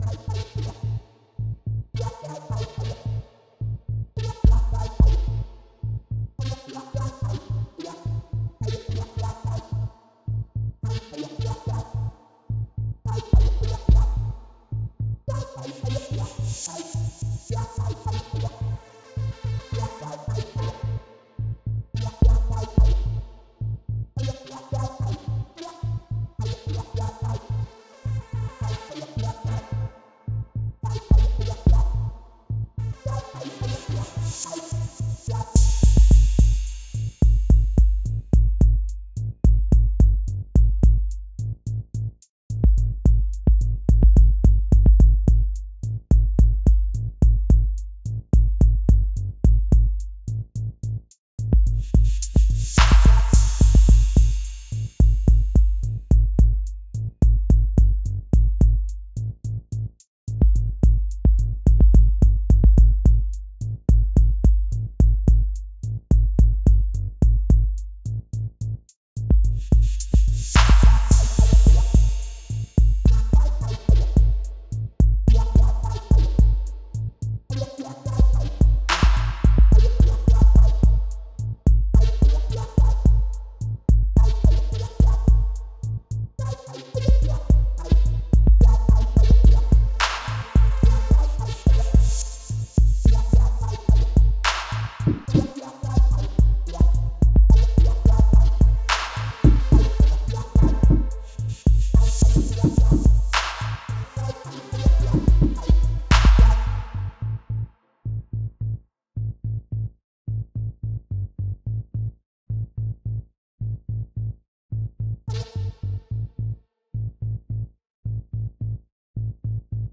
A slow track made for my game